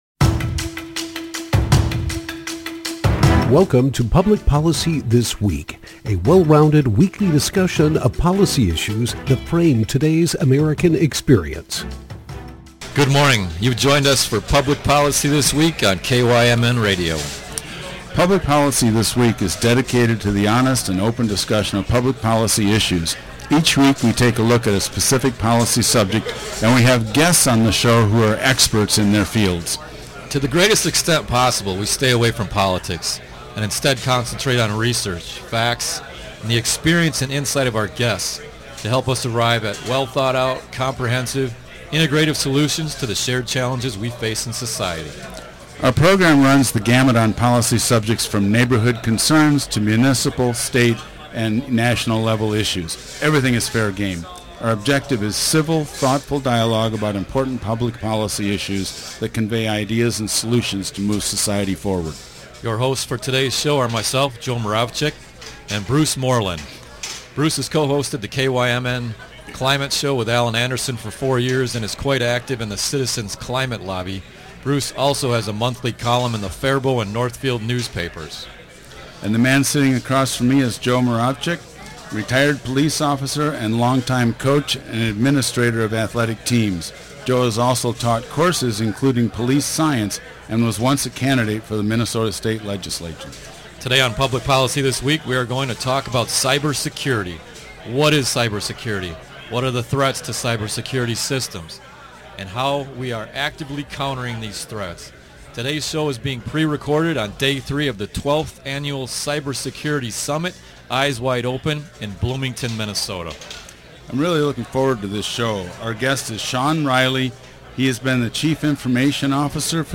Recorded at the 2022 Global Cyber Security Summit on October 26, 2022